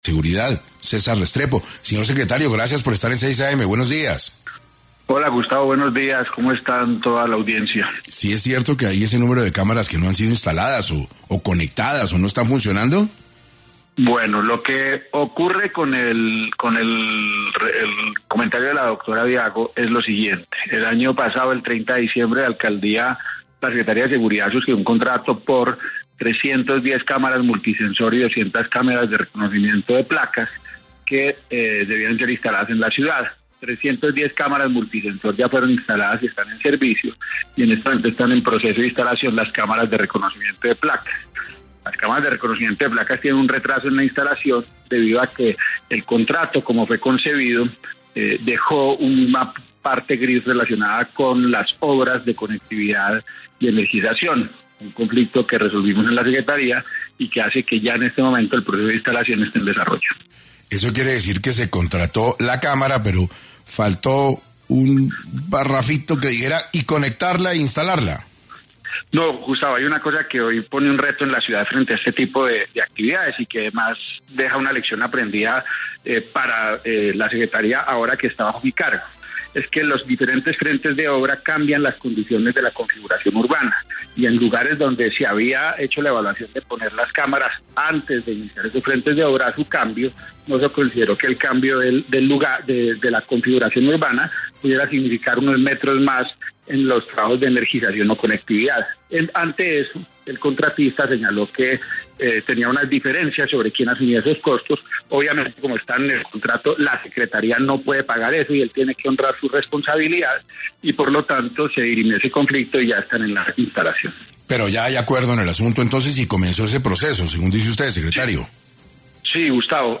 César Restrepo, secretario de seguridad de Bogotá en 6AM
Además, en entrevista en el noticiero ‘6AM’ de Caracol Radio, la concejal expresó el mal uso de las cámaras de velocidad presentes en los corredores viales de la ciudad, siendo necesario la rápida identificación de estas para que los conductores conozcan su ubicación y puedan tener una mejor educación vial.